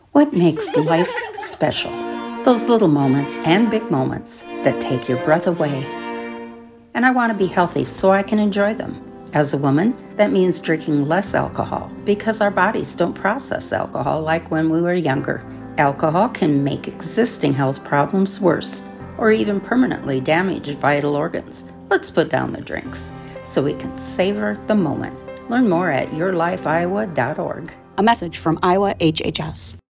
:30 Radio Spot | Savor Every Moment - Special Moments
Savor-the-Moment-Radio-30seconds-Female-Over60.mp3